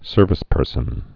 (sûrvĭs-pûrsən)